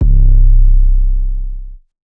PV_808-C ( Sizzle ).wav